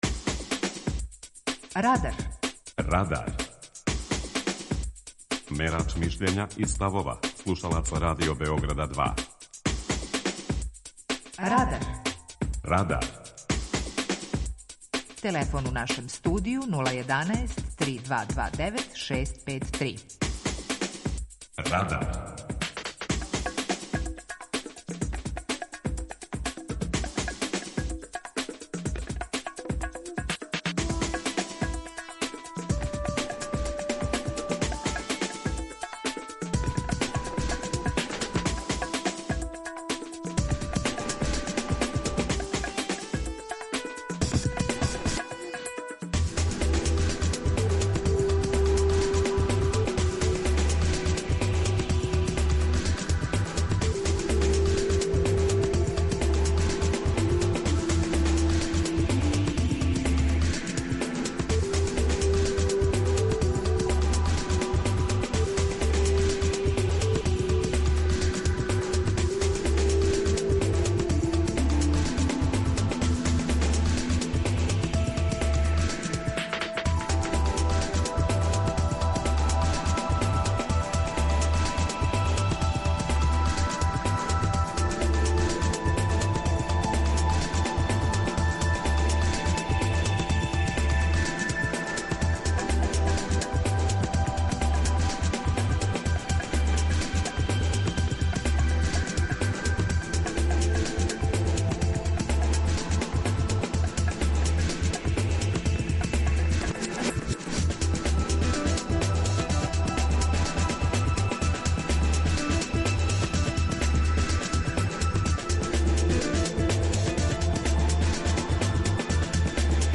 Питање Радара је: Зашто нам је кратко историјско памћење? преузми : 18.84 MB Радар Autor: Група аутора У емисији „Радар", гости и слушаоци разговарају о актуелним темама из друштвеног и културног живота.